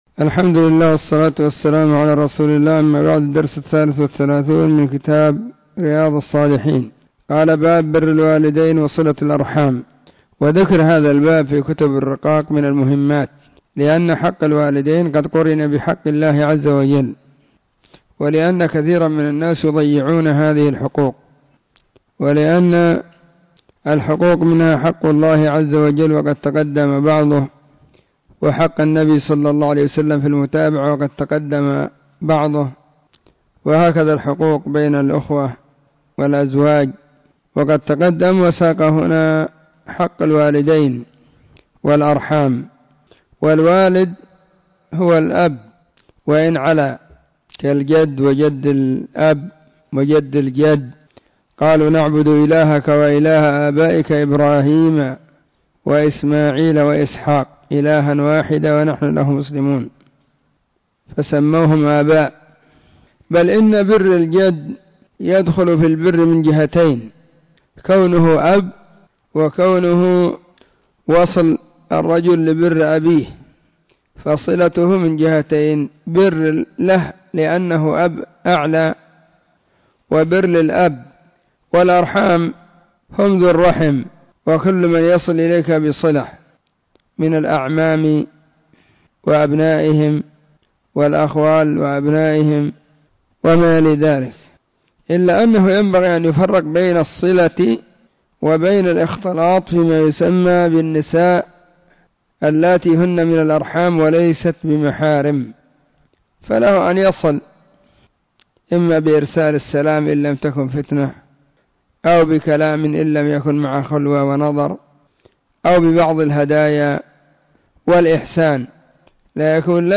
🕐 [بين المغرب والعشاء في كل يوم الخميس]
🕐 [بين المغرب والعشاء في كل يوم الخميس] 📢 مسجد الصحابة – بالغيضة – المهرة، اليمن حرسها الله.